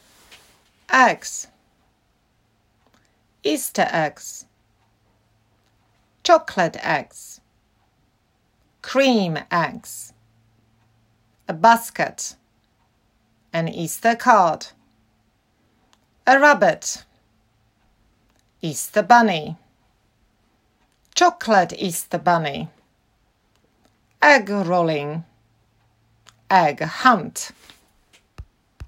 Click play ▶ and repeat after me.
easter-vocab.m4a